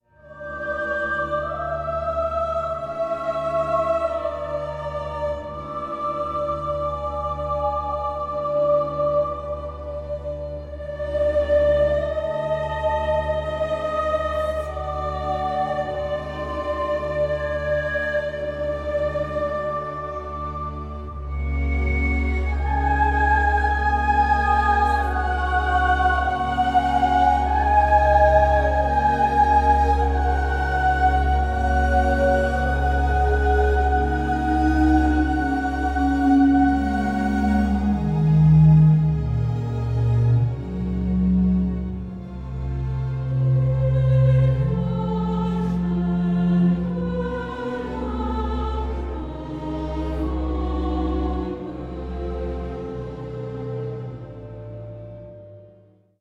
piano
ondes Martenot
sixteen solo strings and eighteen sopranos